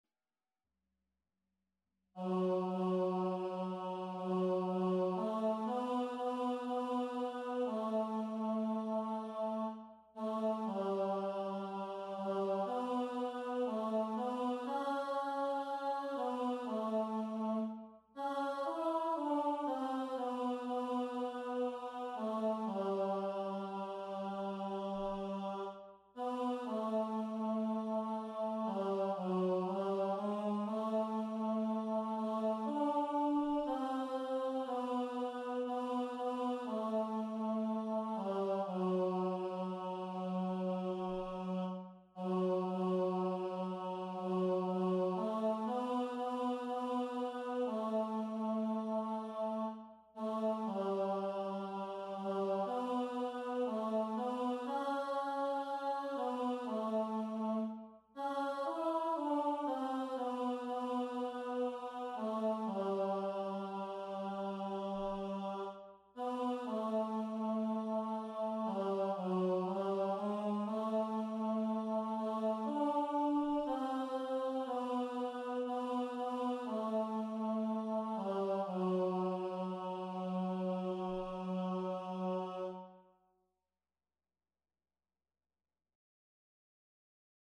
Et-hav-tenor
Et-hav-tenor.mp3